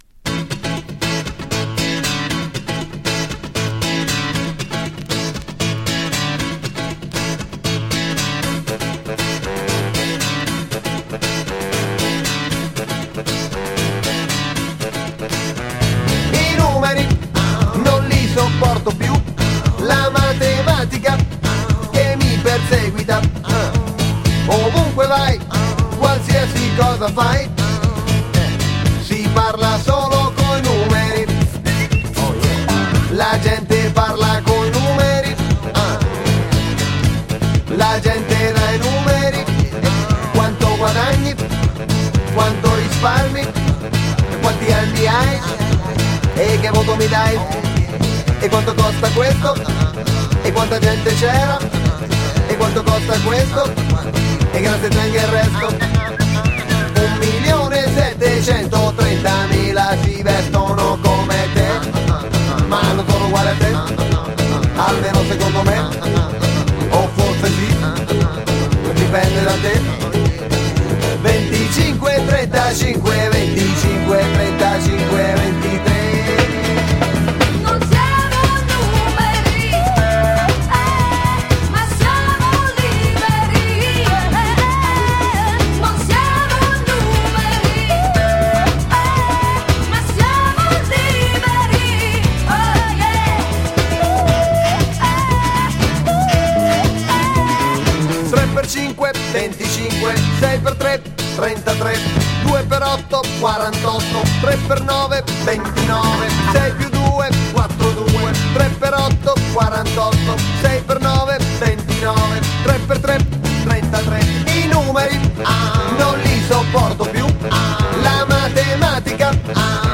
ジャングル・ビートで刻むギターカッティングと4つ打ちキックを絡めたビートがダンサブルな一曲。